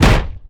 IMPACT_Generic_11_mono.wav